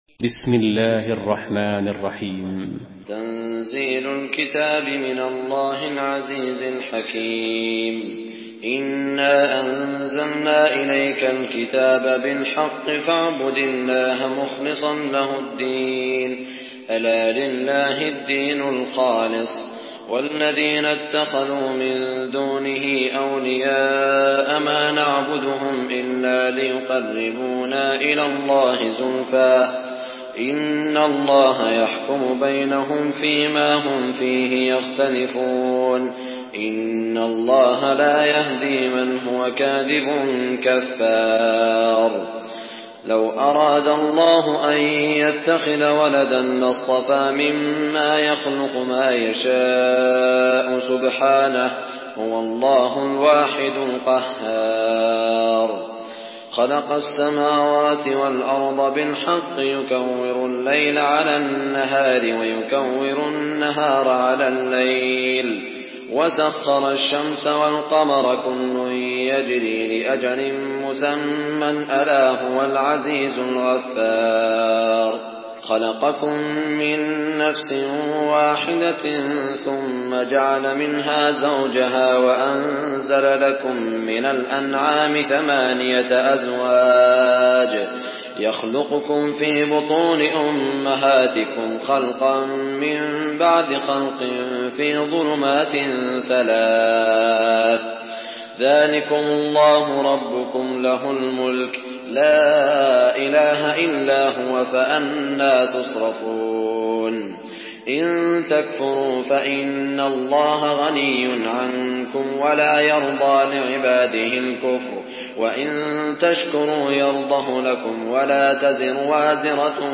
Surah Zümer MP3 by Saud Al Shuraim in Hafs An Asim narration.
Murattal